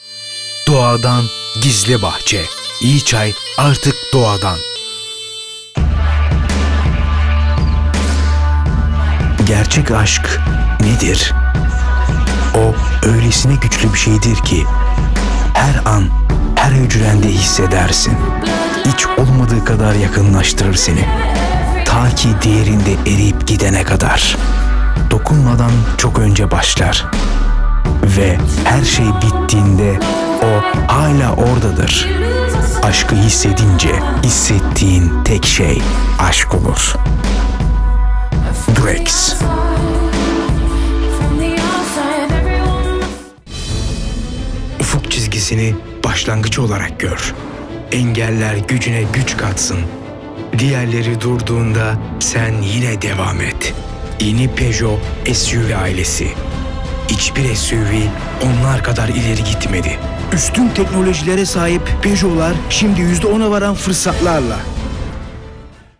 Kein Dialekt